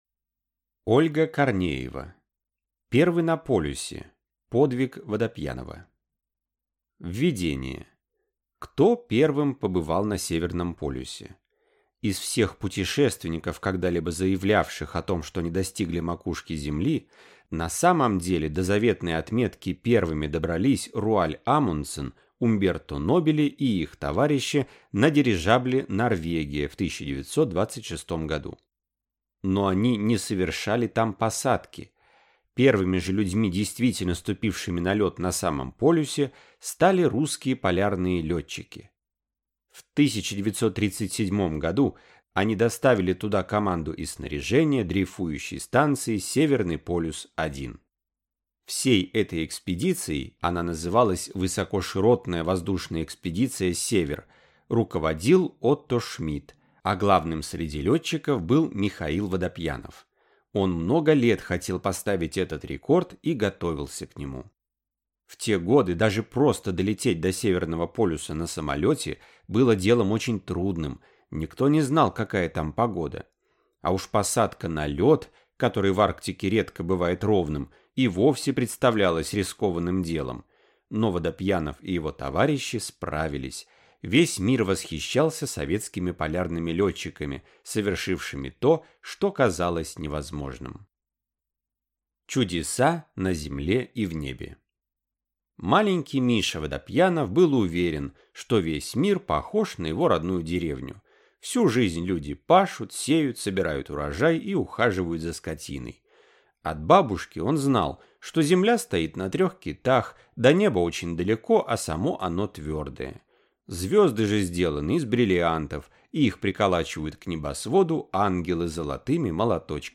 Аудиокнига Первый на полюсе. Подвиг Водопьянова | Библиотека аудиокниг